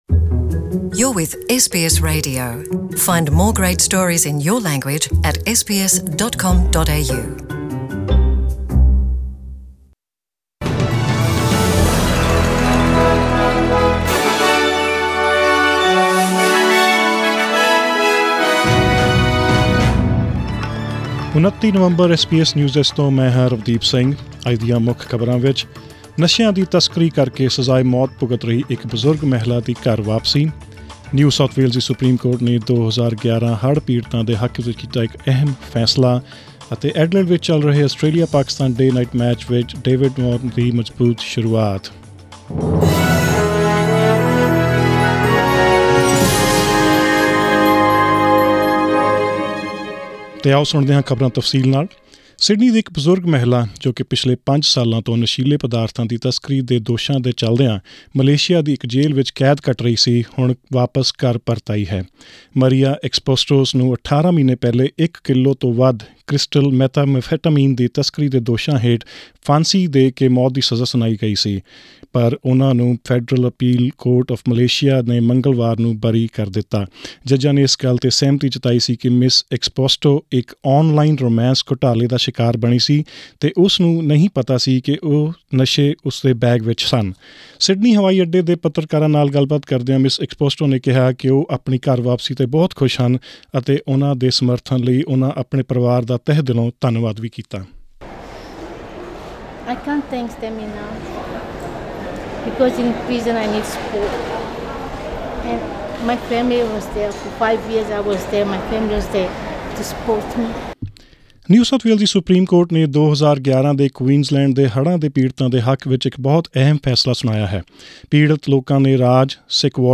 SBS Punjabi News